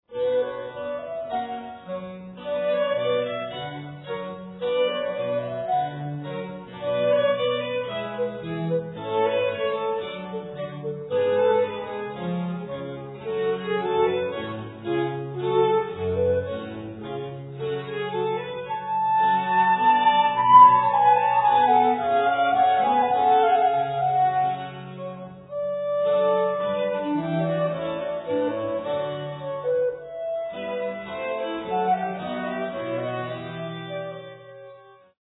Sonata for 2 violins (or 2 flutes) & continuo in C minor